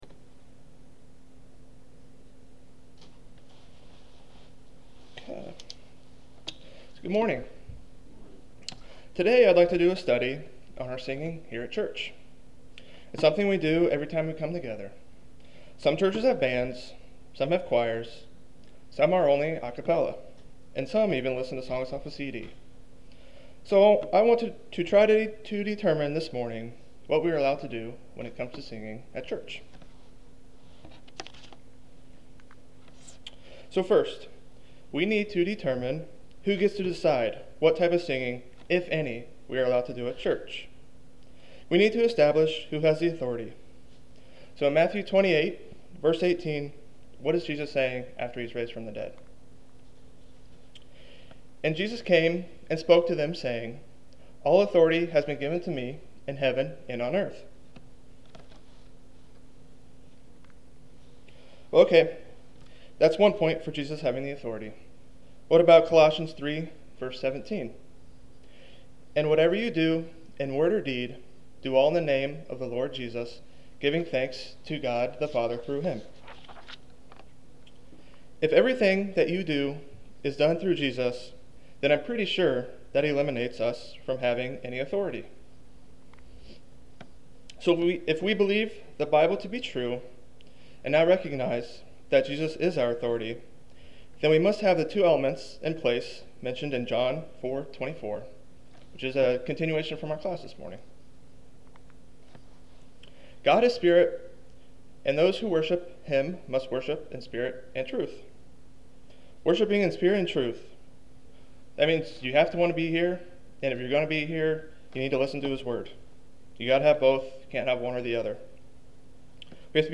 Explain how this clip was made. Passage: Colossians 3:16 Service Type: Sunday AM